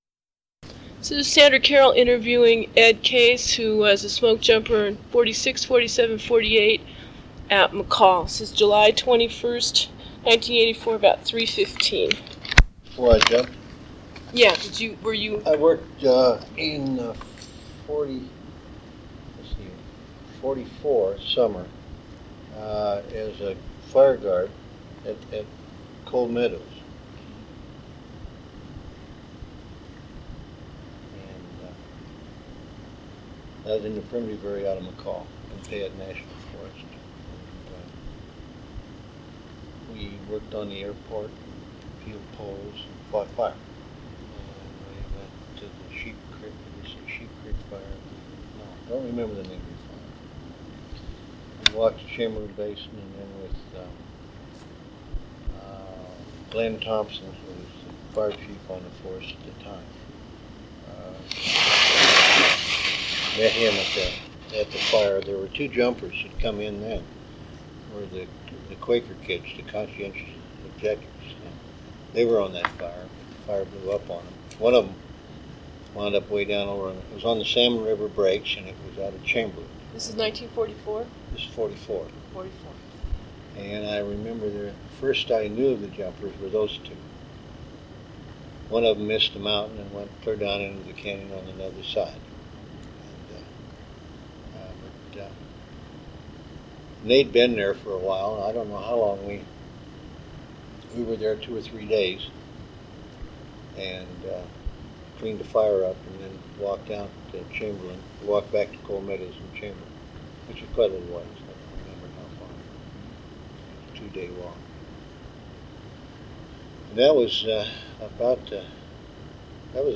Oral History
1 sound cassette (76 min.) : analog